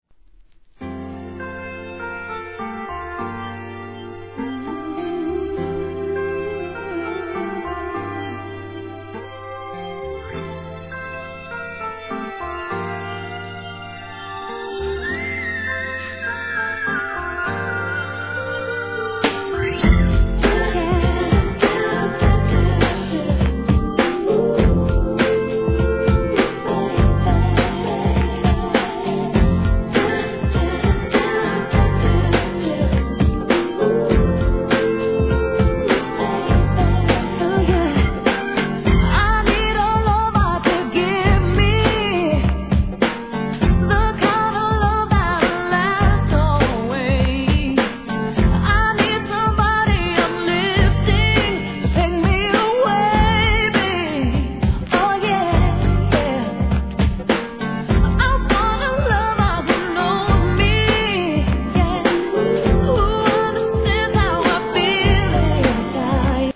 HIP HOP/R&B
そのHIGHヴォイスは神の領域。